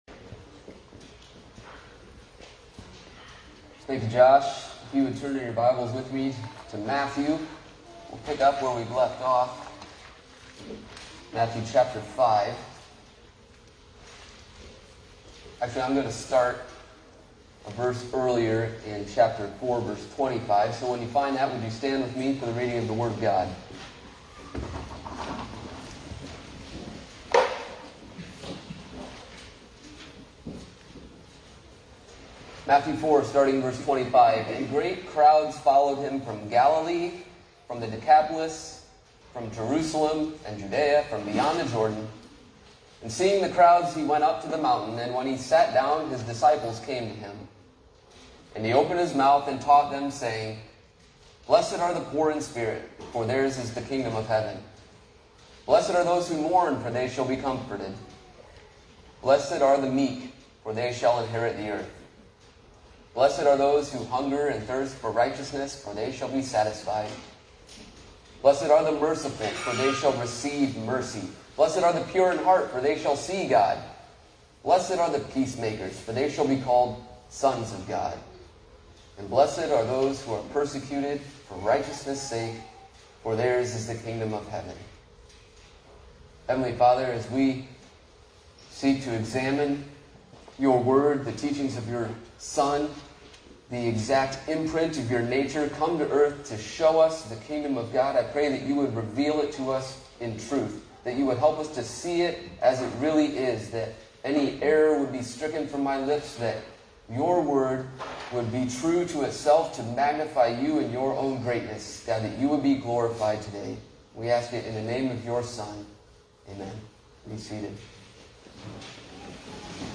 Covenant Reformed Fellowship Sermons